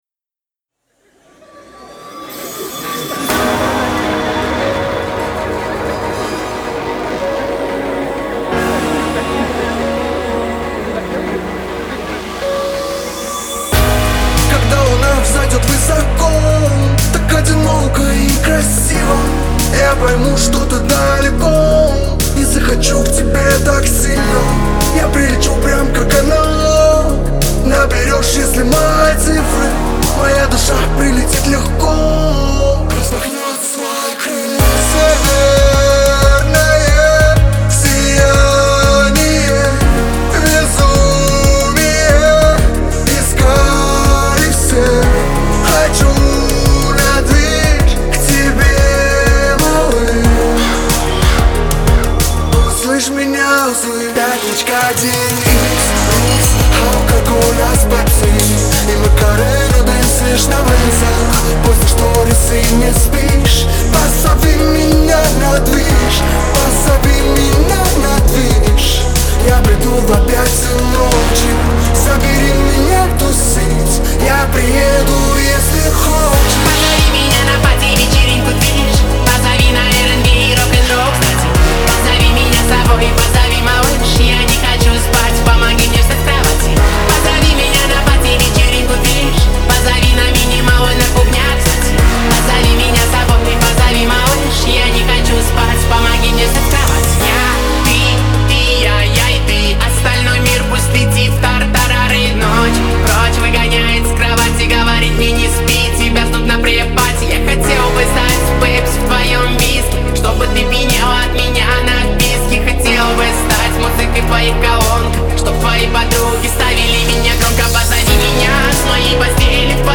это энергичная композиция в жанре хип-хоп
удачно сочетает современные биты с харизматичным исполнением